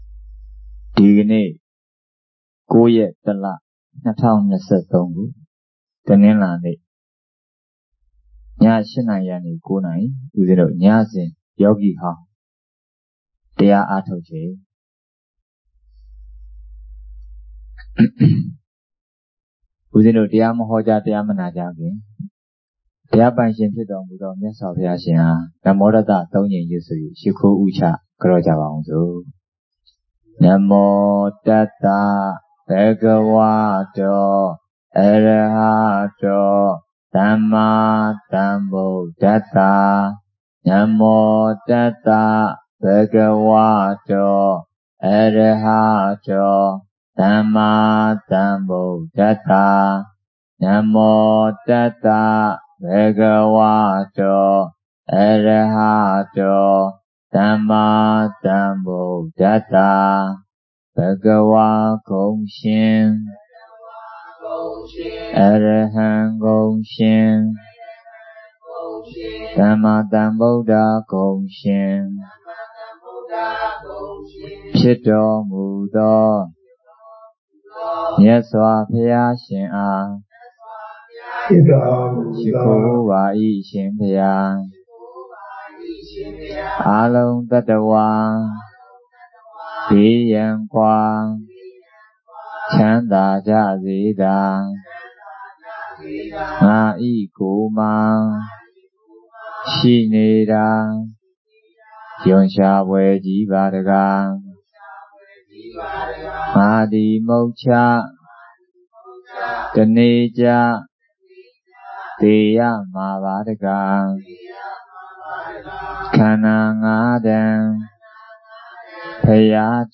Jan09 2023 ညစဉ်တရားပွဲ